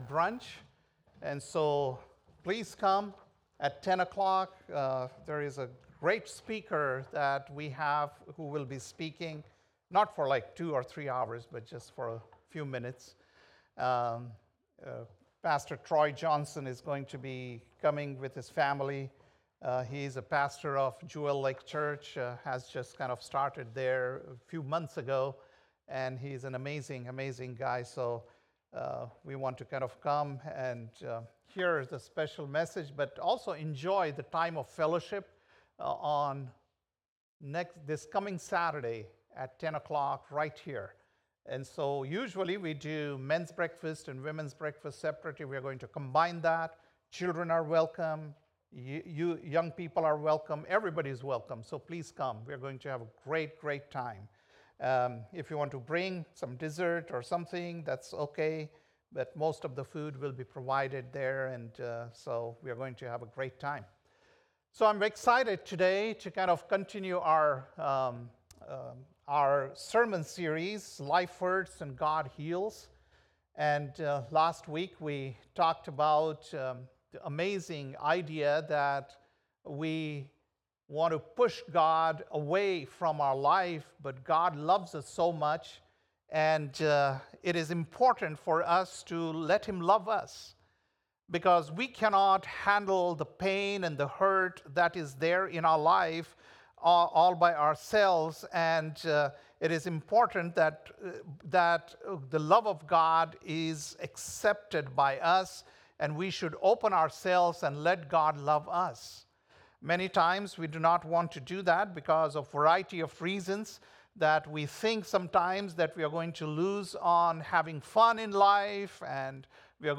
February 9th, 2025 - Sunday Service - Wasilla Lake Church